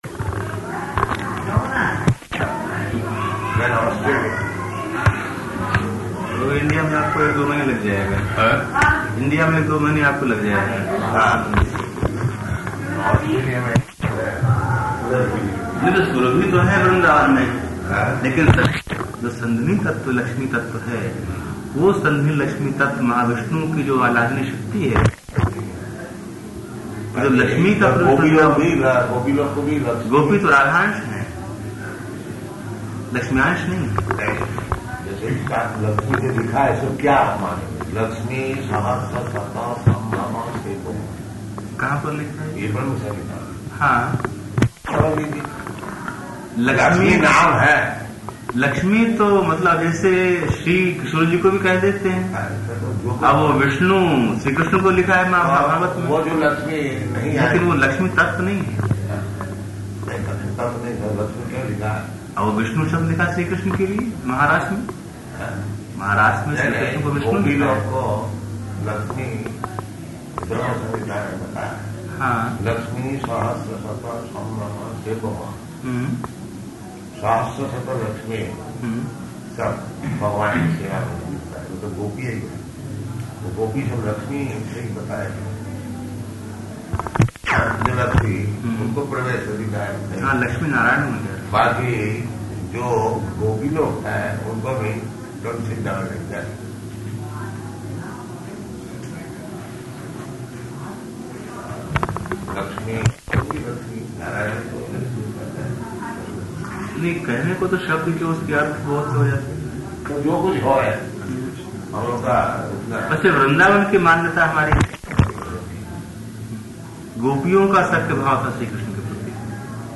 Room Conversation
Room Conversation --:-- --:-- Type: Conversation Dated: March 16th 1974 Location: Vṛndāvana Audio file: 740316R2.VRN.mp3 Prabhupāda: They're Australian.